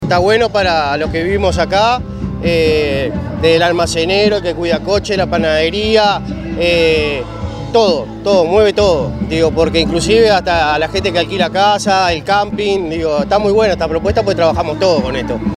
Fiesta de la Cerveza Artesanal en Parque del Plata
vendedor_comida.mp3